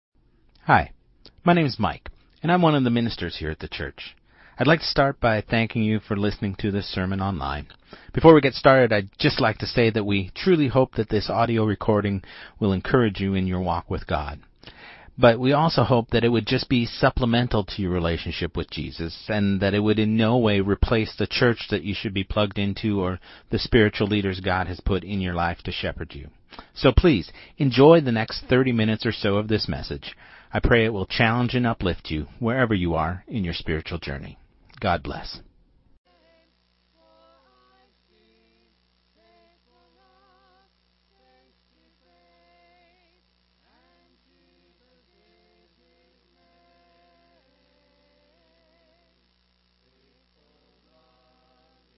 Sermon2025-08-24